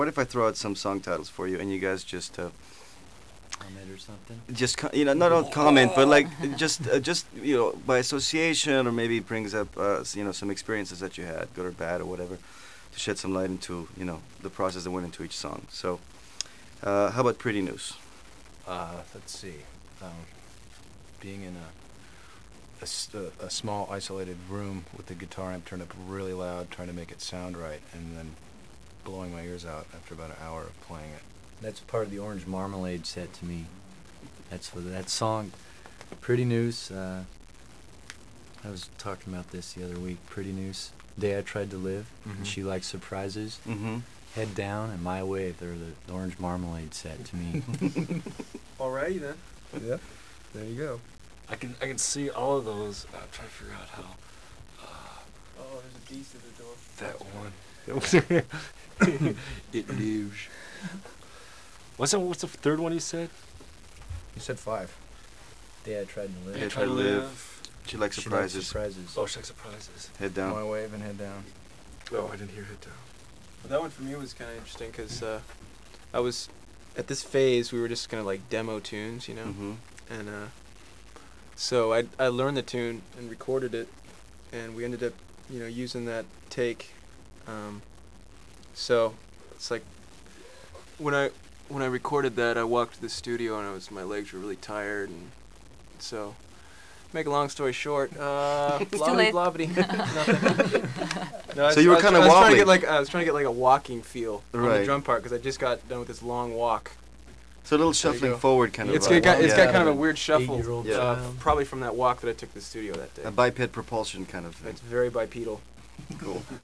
The band discusses "Pretty Noose" 2,595,869